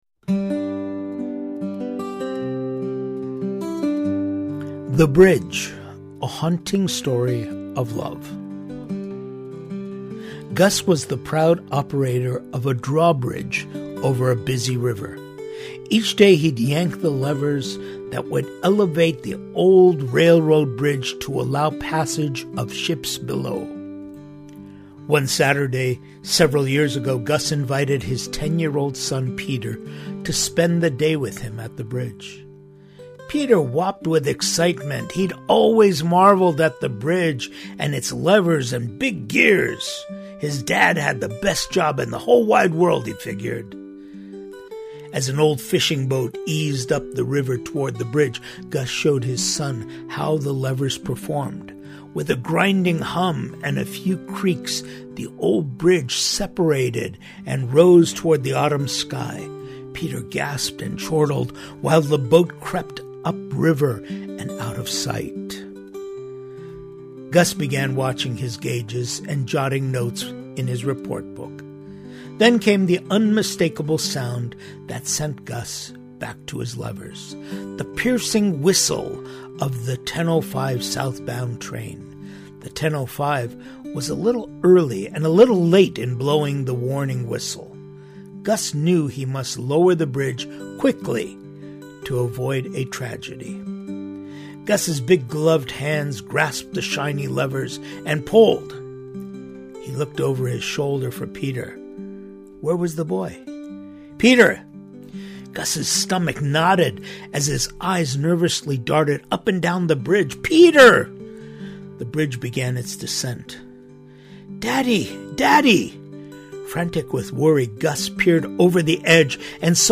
I shared this story as part of my Easter 2025 sermon.